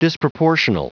Prononciation du mot disproportional en anglais (fichier audio)
Prononciation du mot : disproportional